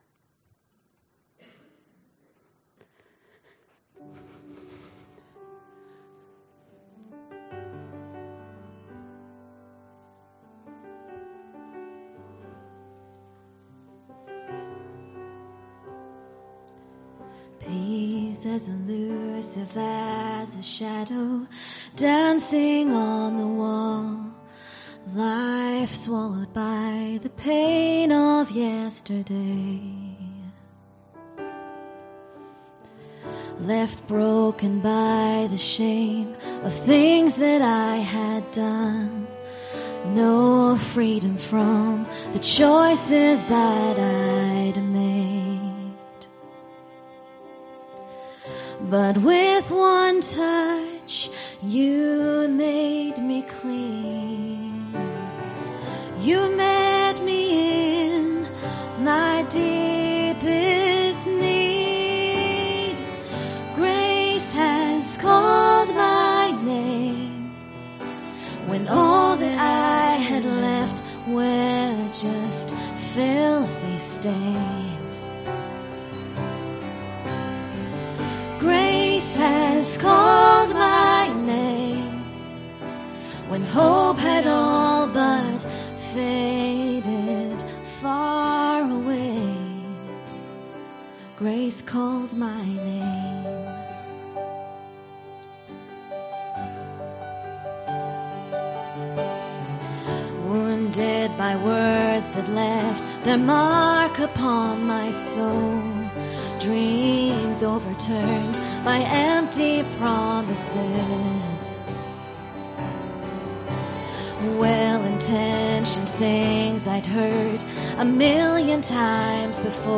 Ministry Song http